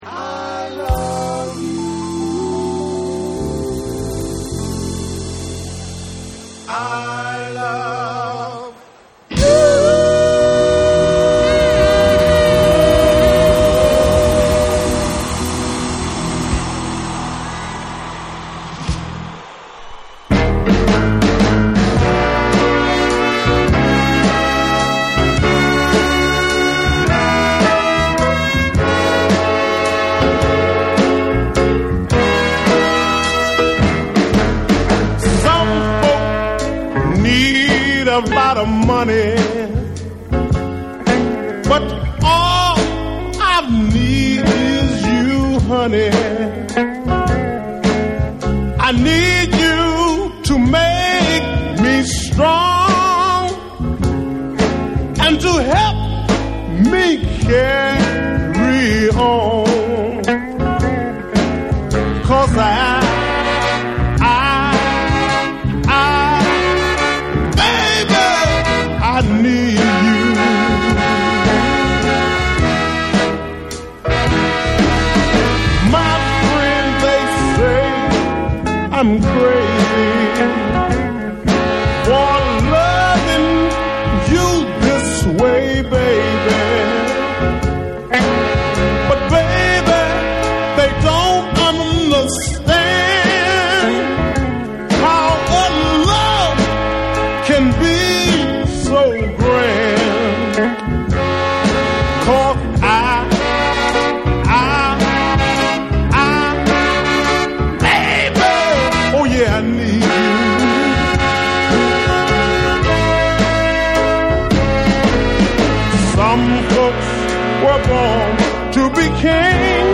SOUL & FUNK & JAZZ & etc / MIX CD